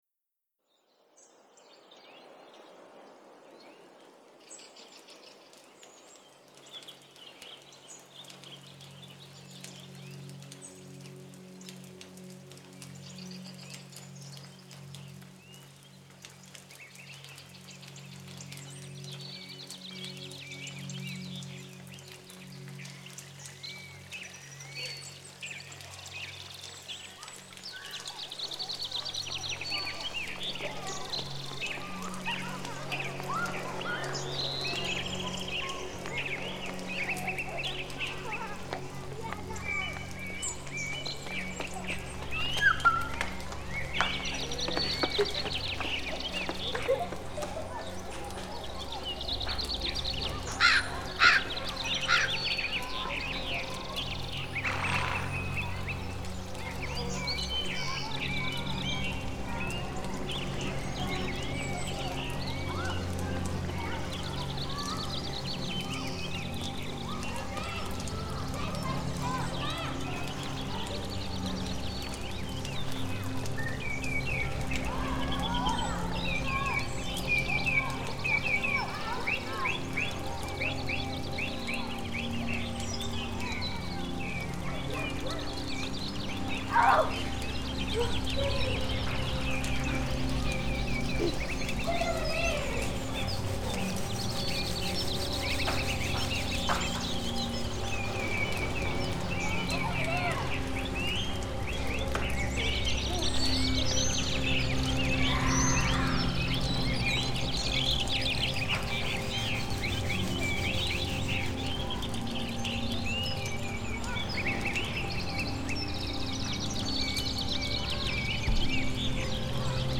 Spring_Ambiance
The Modular Active Adaptive Spring Soundscape.
Spring_Ambiance.mp3